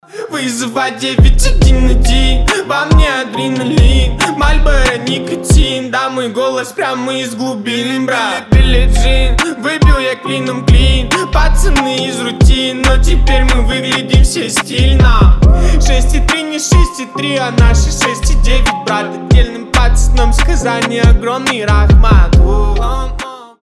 • Качество: 320, Stereo
блатные